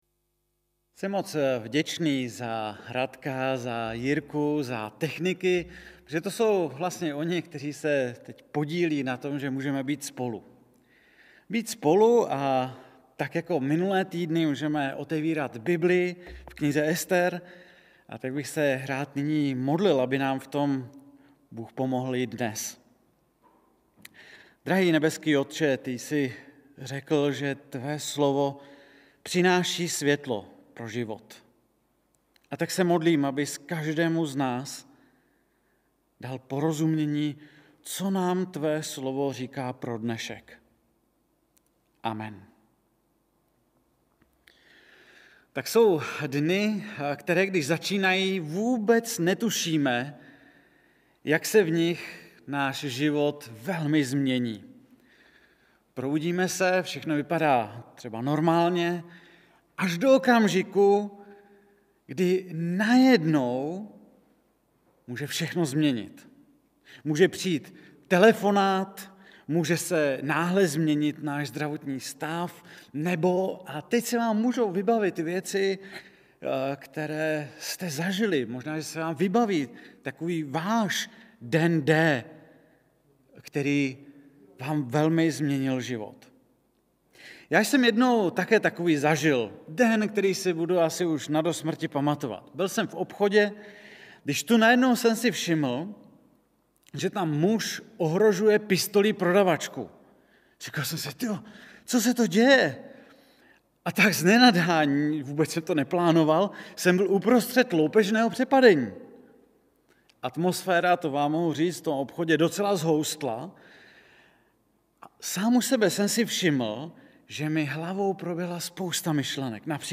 5. díl ze série kázání Ester (6. + 7. kap.)
Kategorie: Nedělní bohoslužby